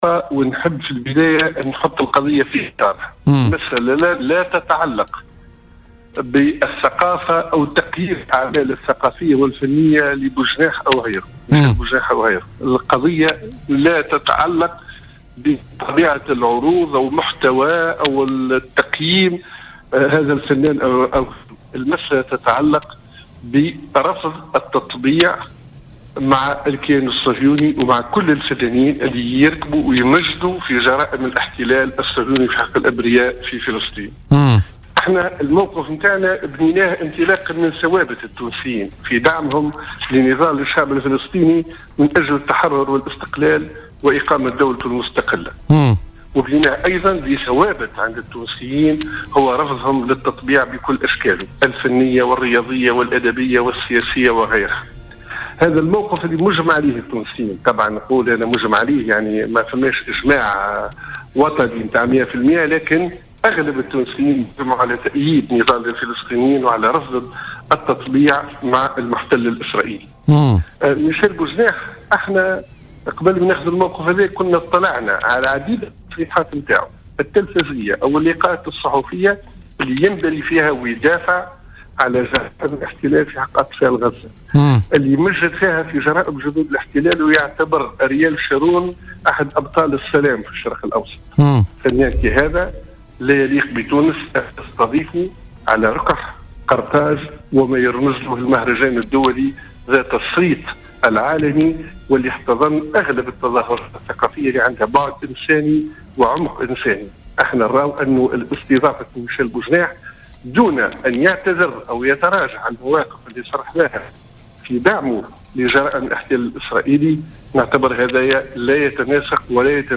وأكد لـ "الجوهرة اف أم" في اتصال هاتفي ببرنامج "بوليتيكا" ان البيان الذي تم اصداره أمس للمطالبة بإلغاء العرض مرده تصريحات سابقة للكوميدي ميشال بوجناح حول دعمه الصّريح الصهيونية والاحتلال الاسرائيلي الذي يتذكر غزة المحاصرة في كل مرة بالقصف.